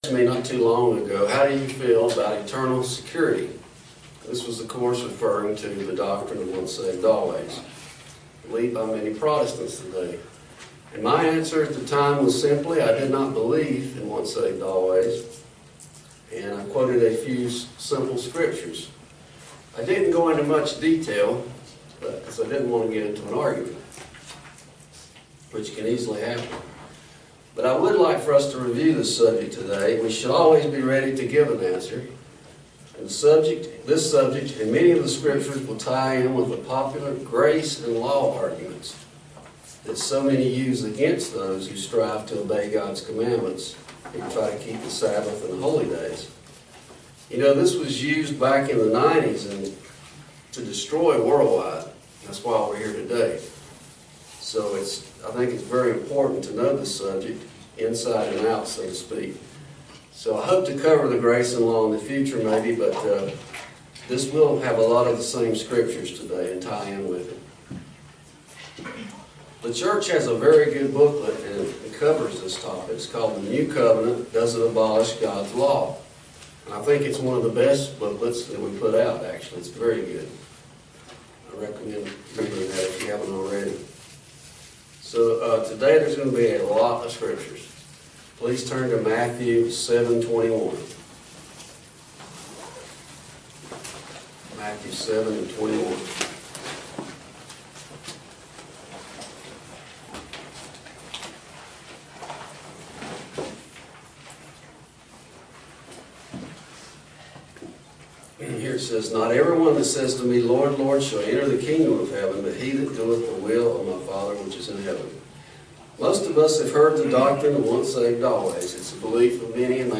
Sermons
Given in Roanoke, VA